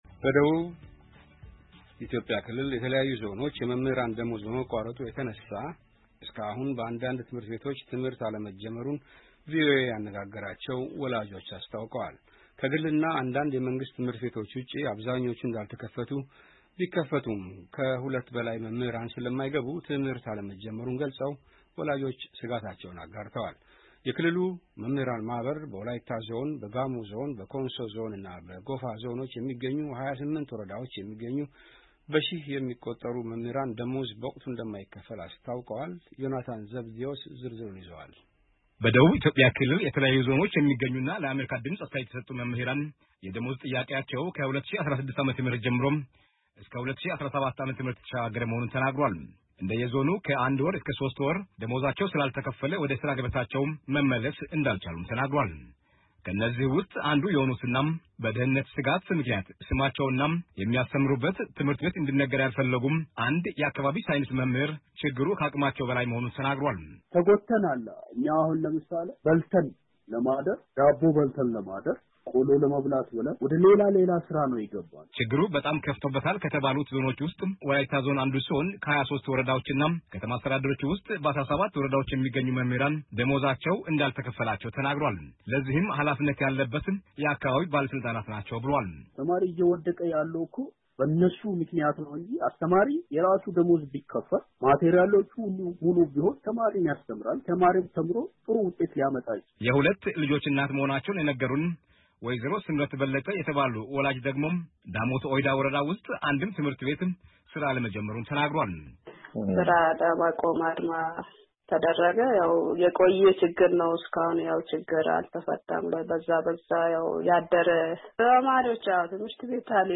በደቡብ ኢትዮጵያ ክልል የተለያዩ ዞኖች የመምህራን ደመወዝ በመቋረጡ የተነሳ እስከ አኹን በአንዳንድ ትምሕር ቤቶች ትምሕርት አለመጀመሩን ቪኦኤ ያነጋገራቸው ወላጆች ገለጹ።